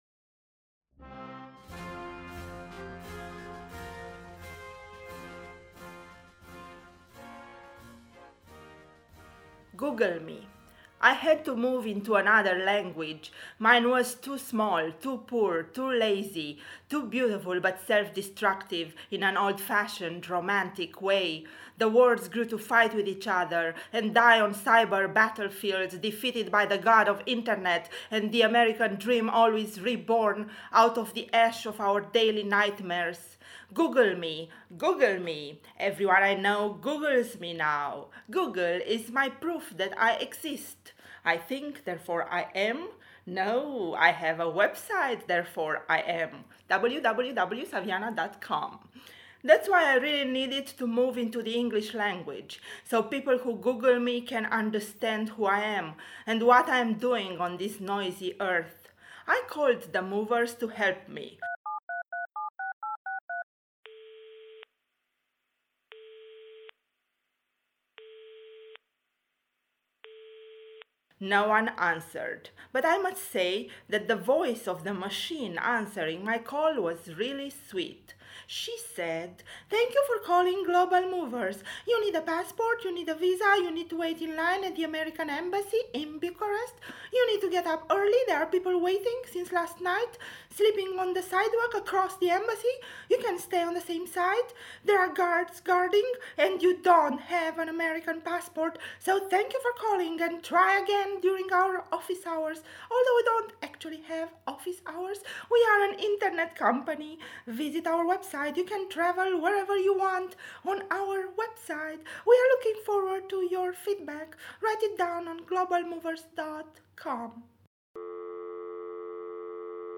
Sound design